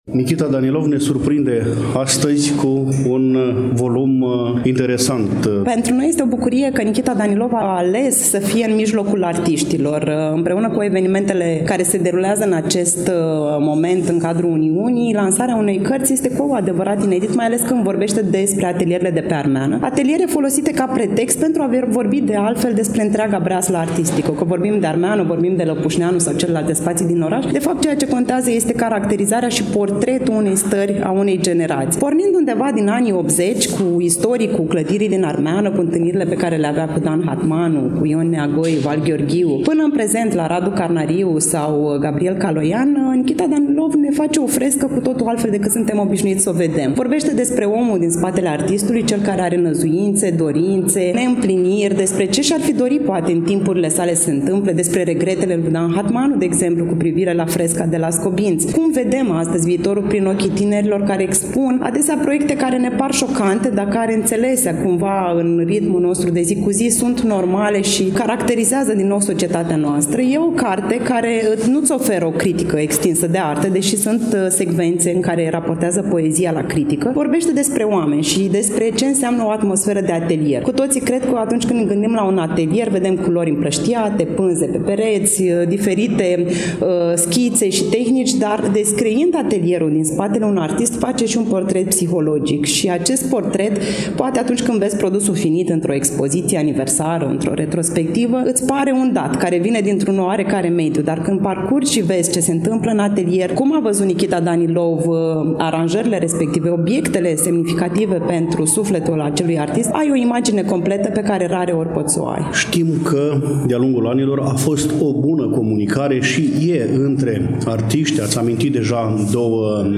Evenimentul s-a desfășurat în ziua de vineri, 5 aprilie 2024, începând cu ora 17 și 30 de minute, în incinta Galeriei de Artă „Th. Pallady” de pe strada Alexandru Lăpușneanu, Numerele 7-9, târgul Iașilor.